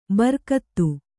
♪ barkattu